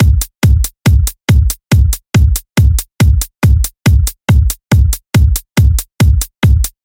恍惚的踢踏鼓
描述：这是一个只为140bpm/tempo曲目制作的踢鼓循环。